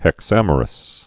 (hĕk-sămər-əs)